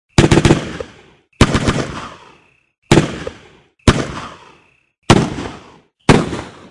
军乐声 " M16爆裂声
描述：航母上的步枪训练。
标签： 半自动 突发 射击 军事 M16 连发 自动 步枪 武器 汽车 消防 军队 子弹 射击 射击
声道立体声